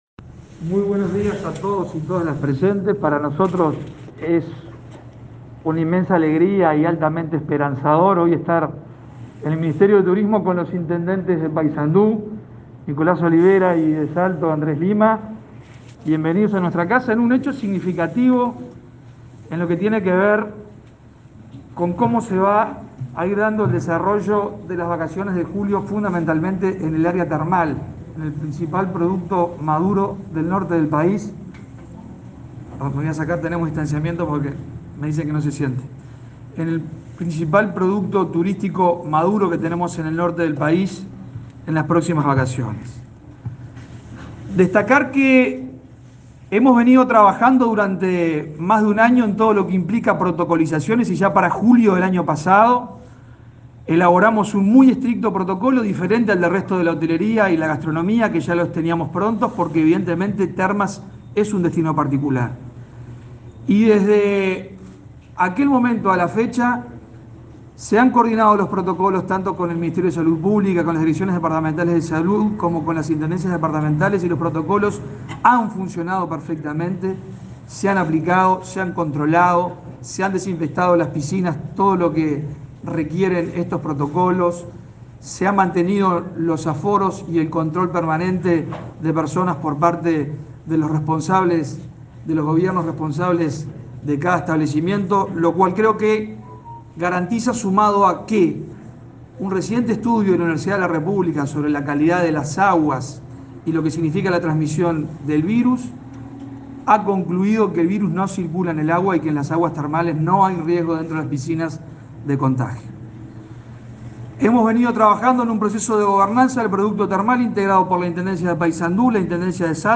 Cardoso brindó declaraciones a la prensa, este martes 22, tras finalizar la conferencia en la que informó acerca de los protocolos sanitarios vigentes